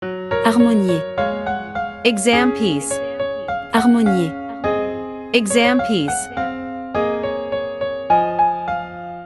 • 人声数拍
• 大师演奏范例